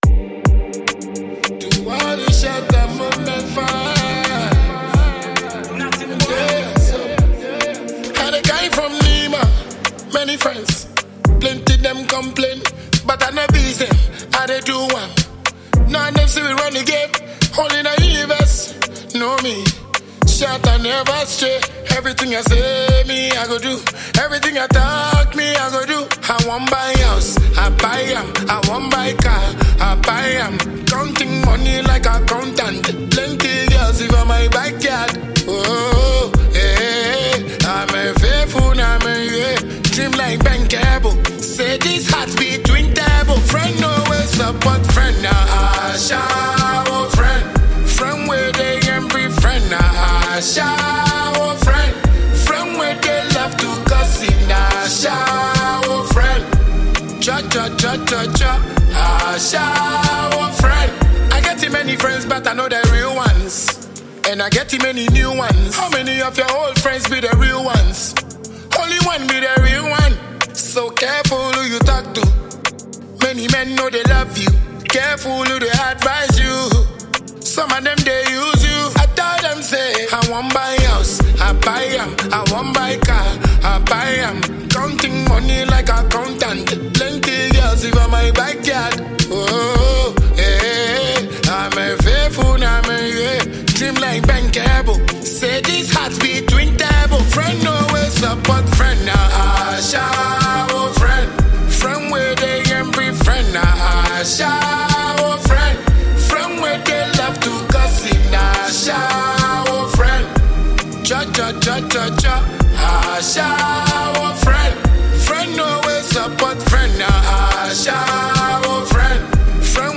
Ghanaian dancehall king